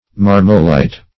Search Result for " marmolite" : The Collaborative International Dictionary of English v.0.48: Marmolite \Mar"mo*lite\ (m[aum]r"m[-o]*l[imac]t), n. [Gr. maramai`rein to sparkle + -lite.]